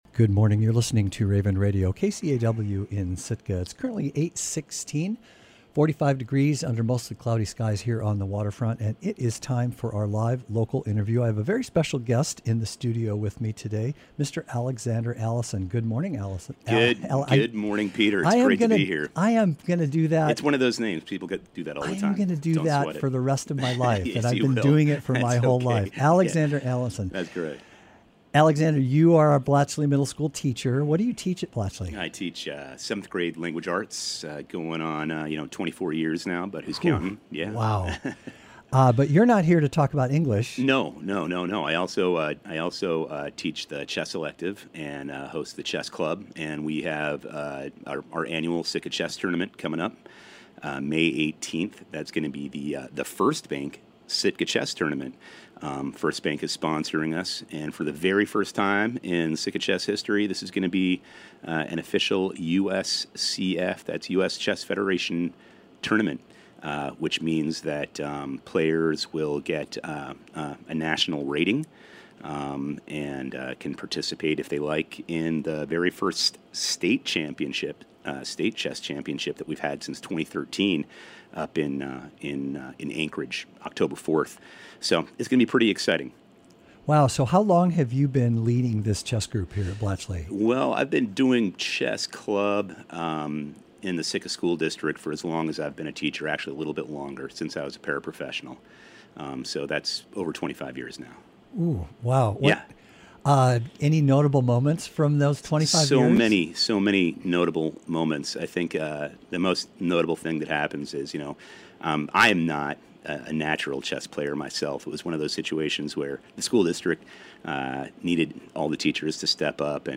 Morning Interview